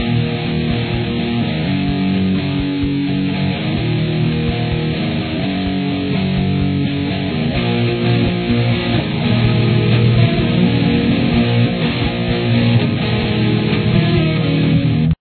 The tempo for the bridge is 126.
Bridge
E5
G5
A5/B